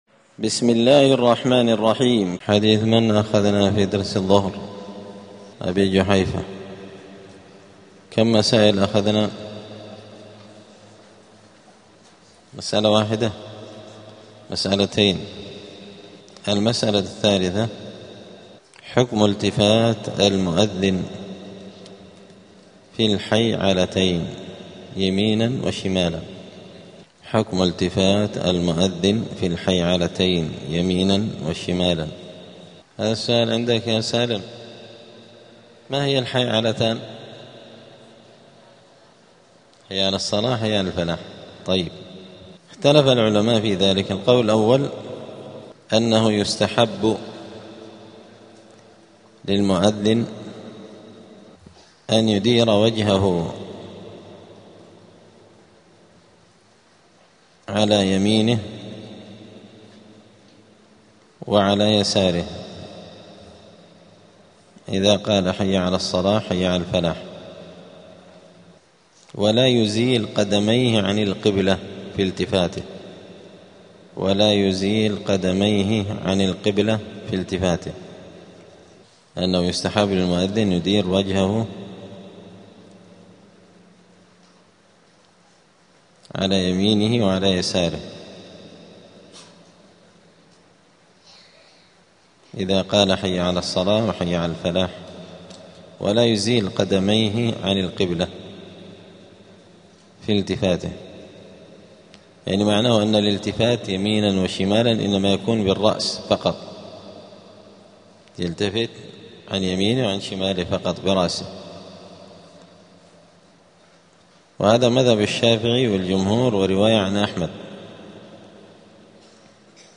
دار الحديث السلفية بمسجد الفرقان قشن المهرة اليمن
*الدرس الواحد والخمسون بعد المائة [151] باب الأذان {حكم التفات المؤذن بالحيعلتين يمينا و شمالا}*